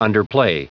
Prononciation du mot underplay en anglais (fichier audio)
Prononciation du mot : underplay